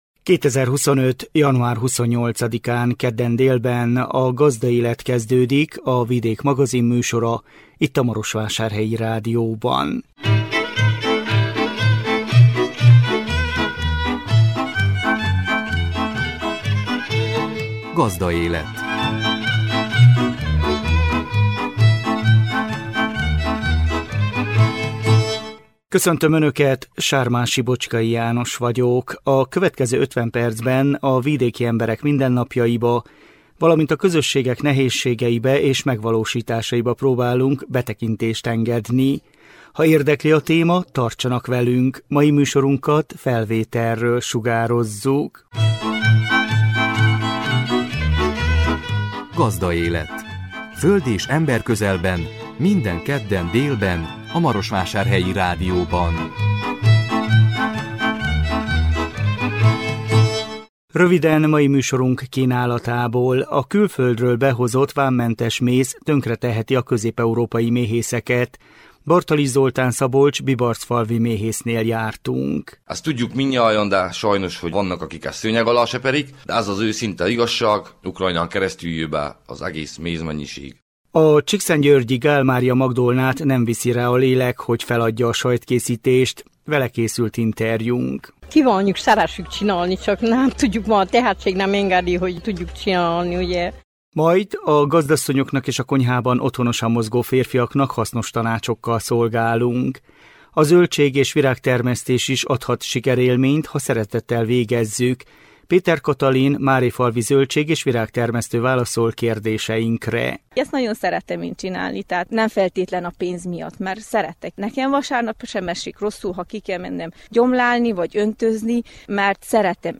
Vele készült interjúnk.
A hangos meghívót Ravasz Ferenc alpolgármester adja át.